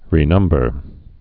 (rē-nŭmbər)